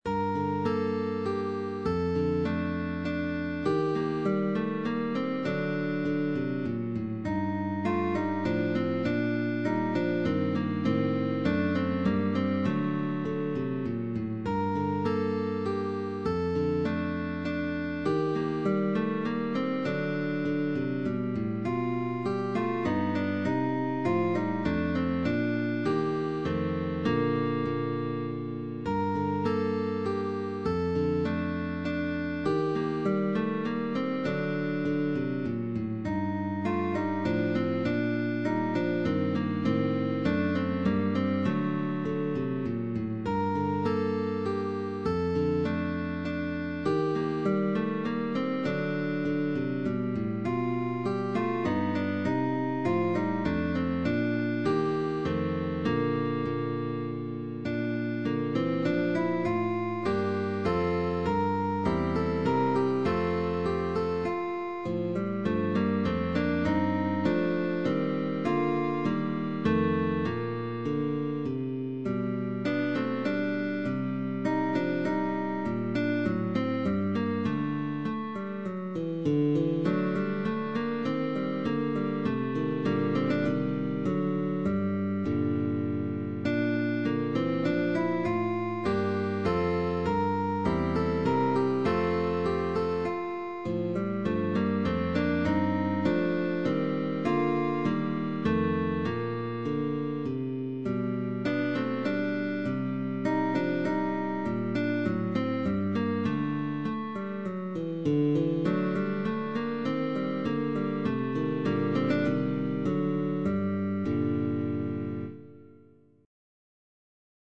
Barroco